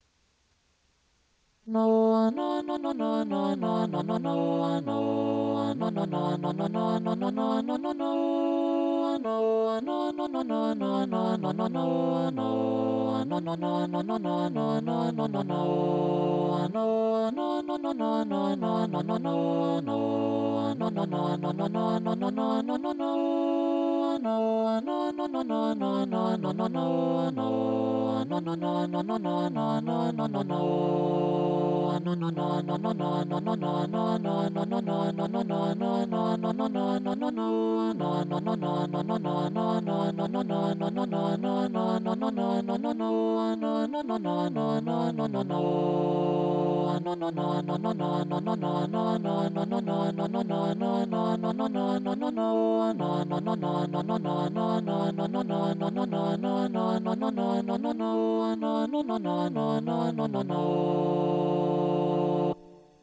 Scandinavian folk music style.
Swedish polska, two voices, homophonous Download
hummed on [no:].